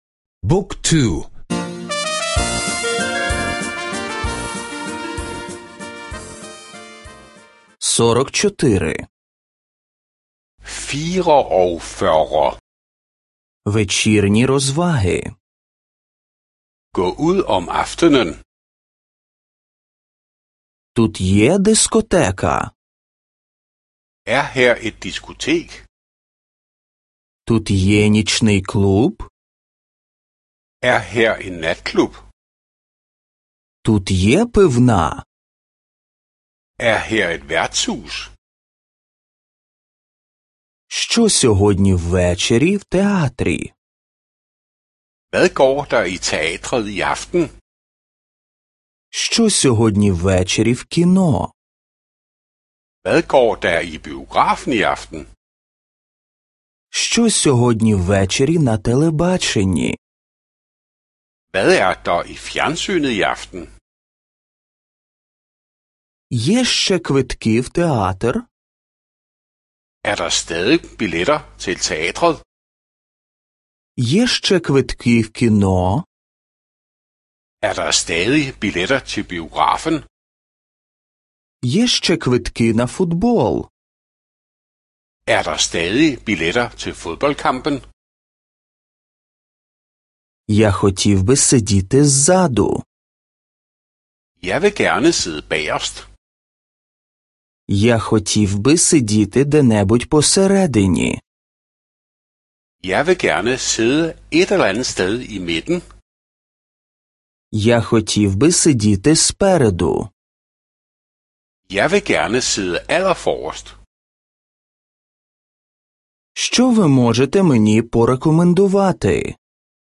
Аудіо уроки данської мови — слухати онлайн